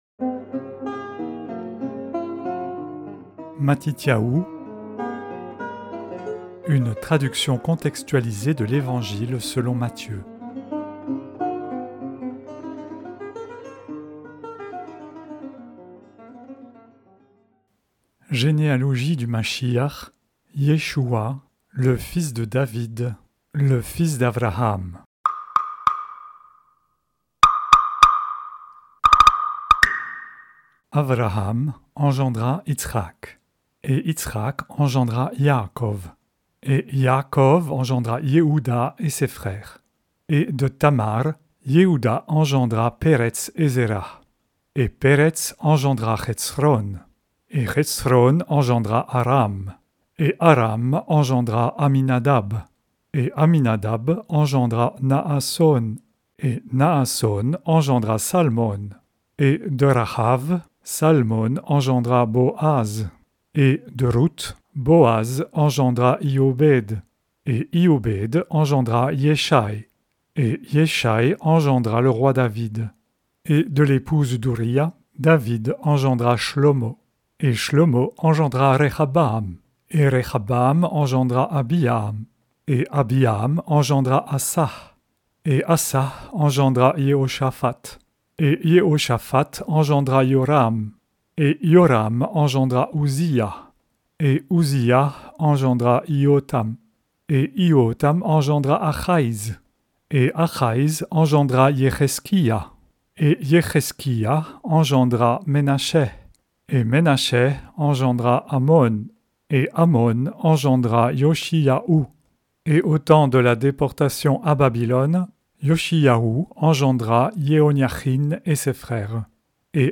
Vous pouvez en découvrir la saveur avec un extrait du livre lu à haute voix: → écouter